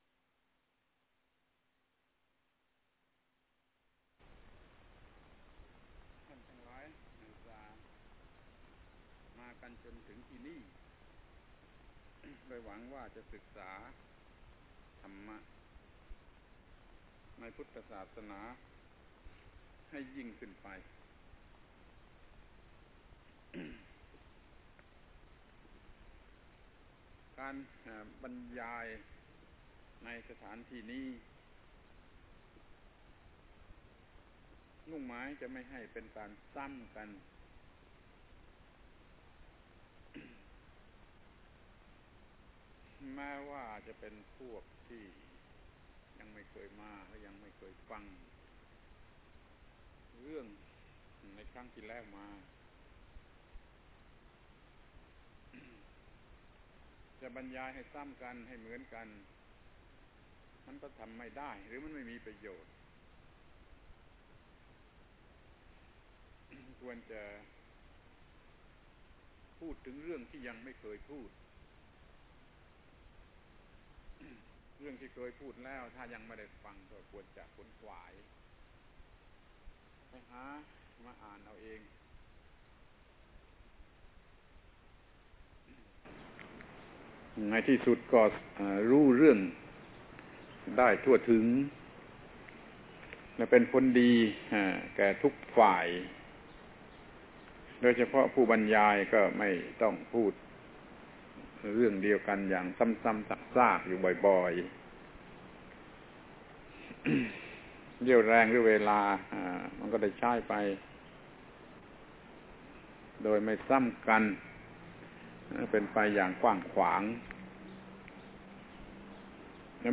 พระธรรมโกศาจารย์ (พุทธทาสภิกขุ) - อบรมนักศึกษามหาวิทยาลัยศรีนรินทรวิโรฒ ปี 2519 ครั้ง 1 ดูพุทธศาสนาคราวเดียวหมด